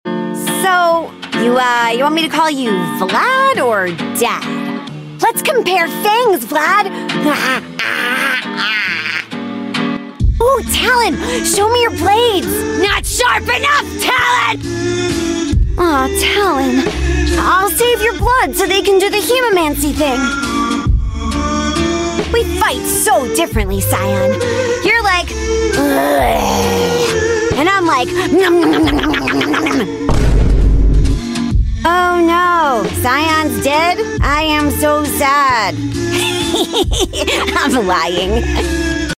I found Briar's voice line sound effects free download